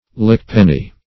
Search Result for " lickpenny" : The Collaborative International Dictionary of English v.0.48: Lickpenny \Lick"pen`ny\ (-p[e^]n`n[y^]), n. A devourer or absorber of money.